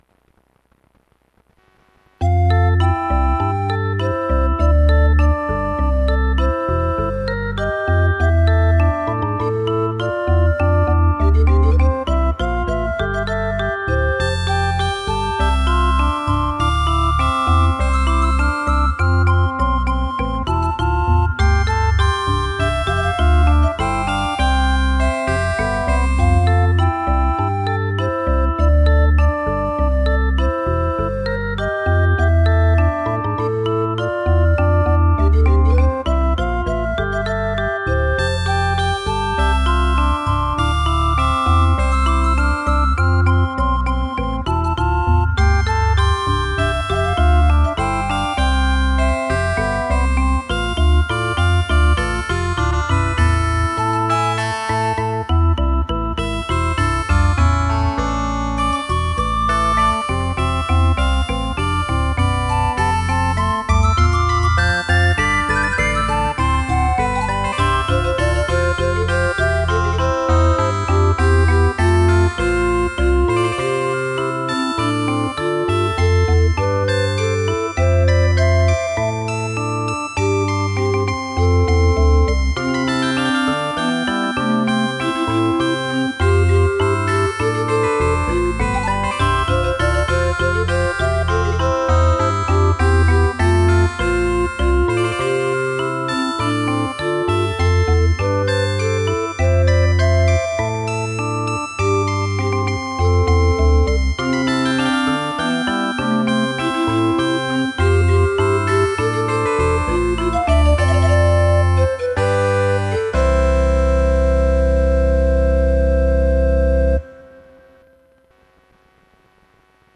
POLYPHONIC MUSIC ; SACRED MUSIC